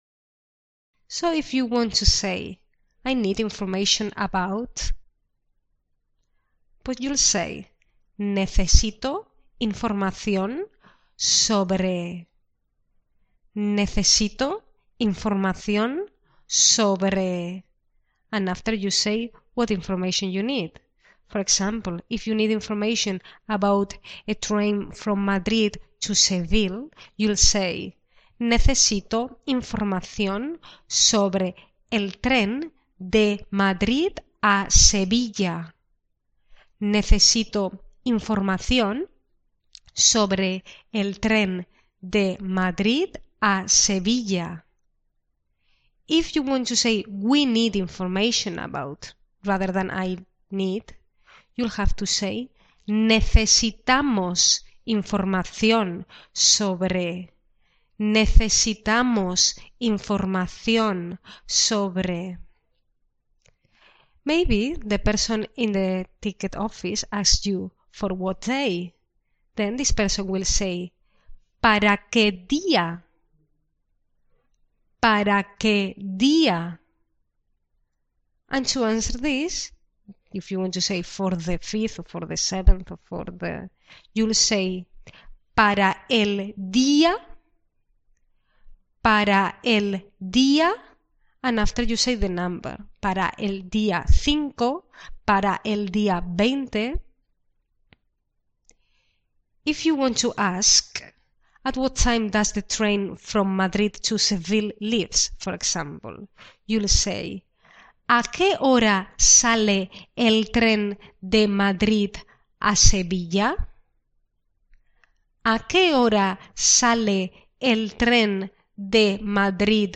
Pronunciation